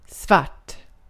Ääntäminen
IPA: /ˈsvart/ IPA: [ˈsvaʈ]